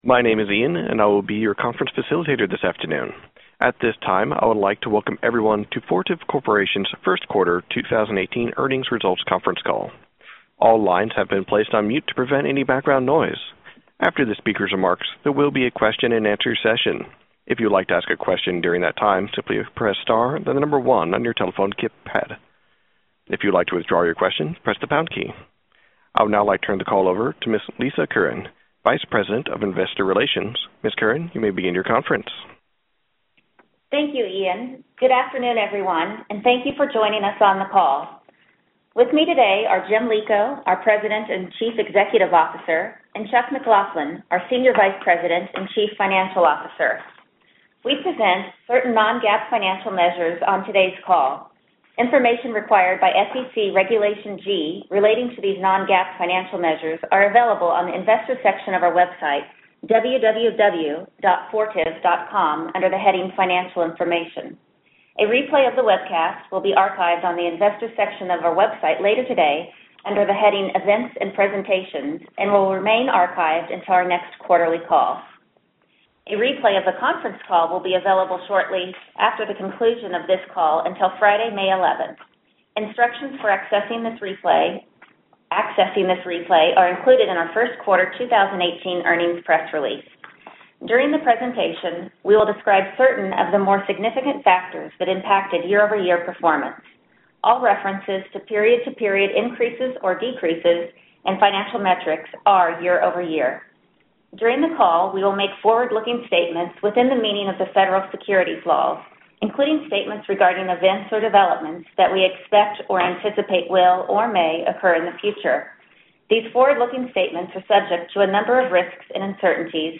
Earnings Webcast Q1 2018 Audio
Q1_2018_earnings_call_replay.mp3